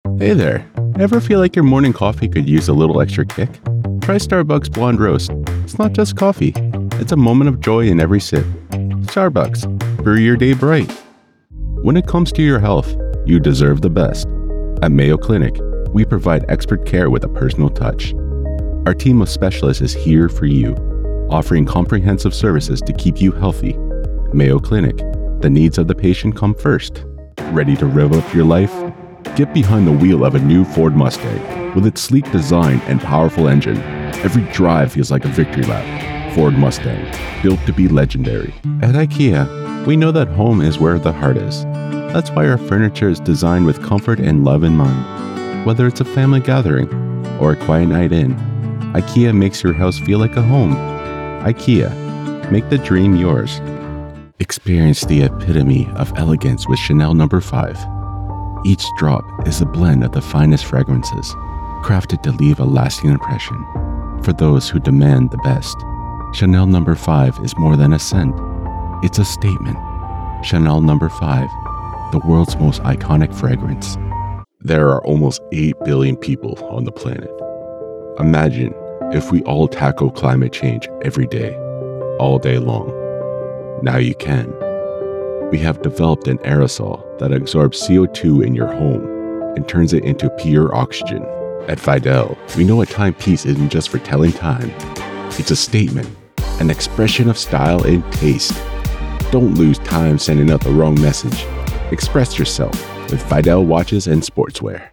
Adult (30-50) | Older Sound (50+)
The Voice Realm represents professional and affordable American and Canadian voice talent with authentic North American accents suited to international voice castings, from small jobs to international campaigns.
Our voice over talent record in their professional studios, so you save money!